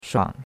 shuang3.mp3